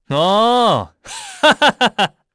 Lusikiel-Vox_Happy4_kr.wav